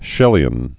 (shĕlē-ən)